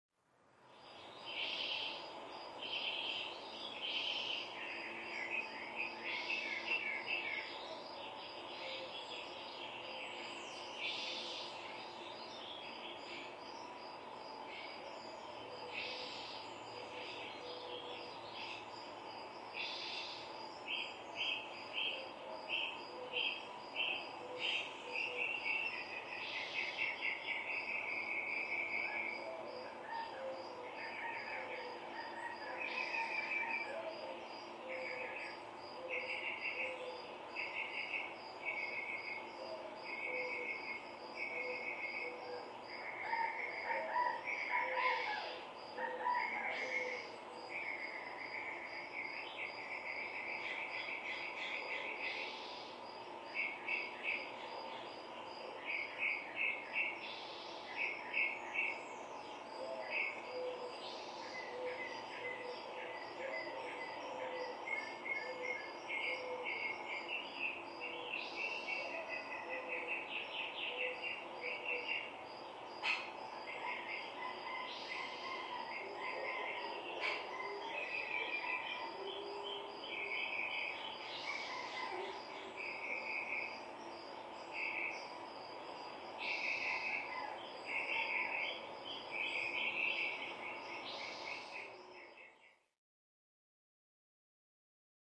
Birds; Day Ambience, Through Open Window.